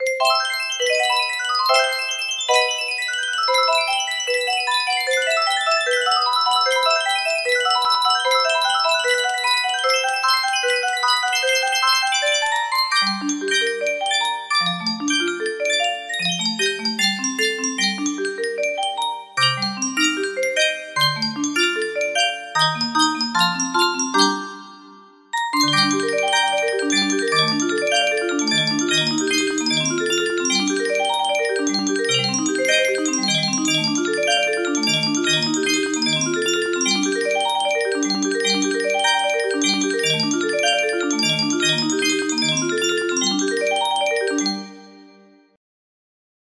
un music box melody
Full range 60